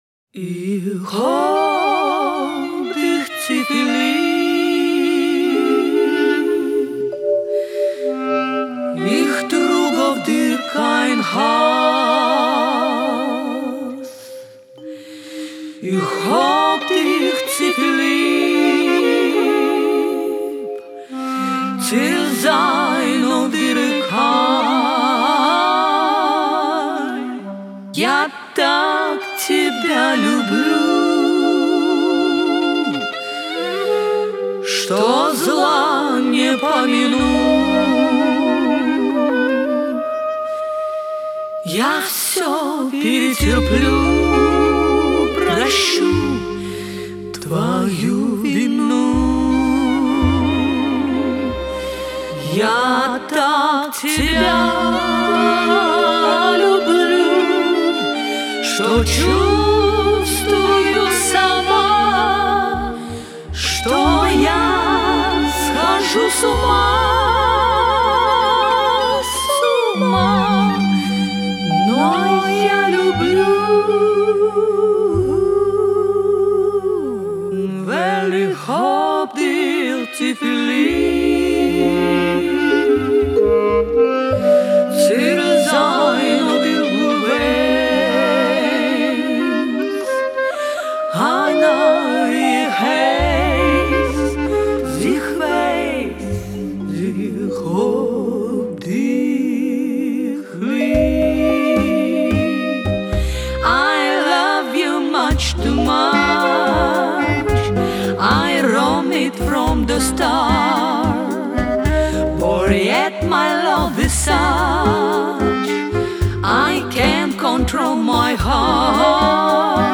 куда и вошло известное танго на идиш